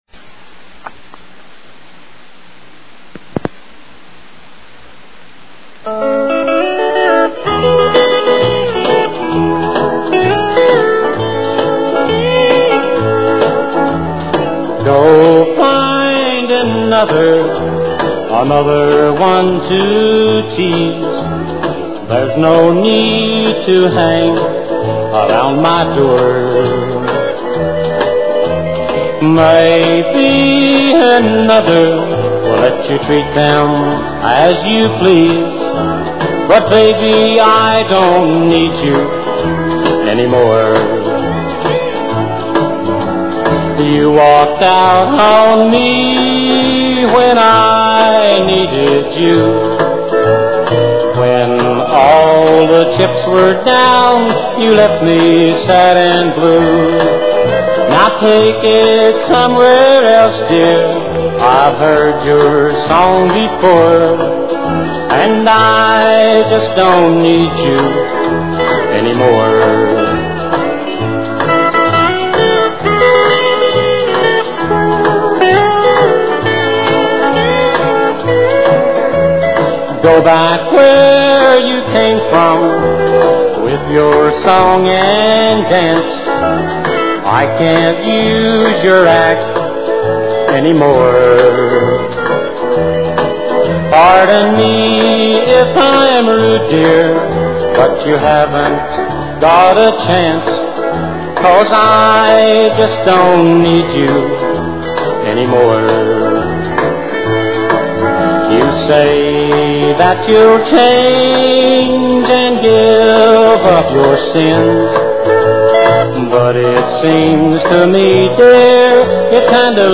A Tribute To Old Time Country Music
plays some very impressive guitar along with his singing